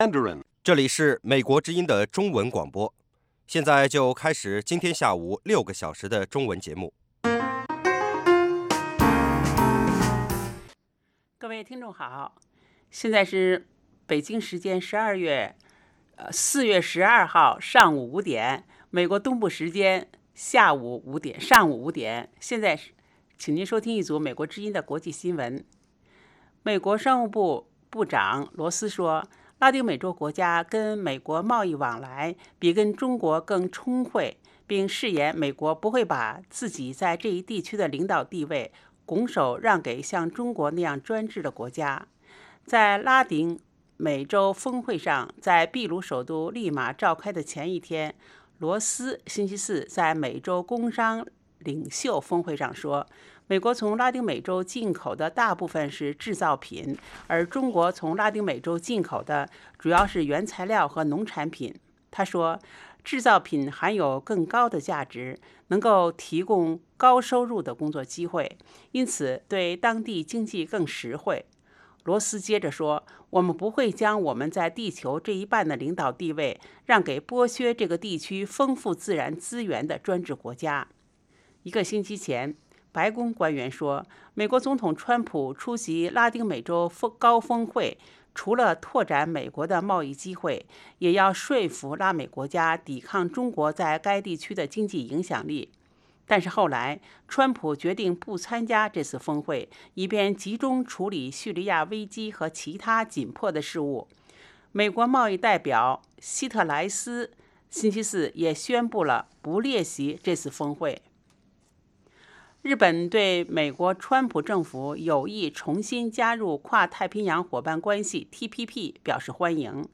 北京时间下午5-6点广播节目。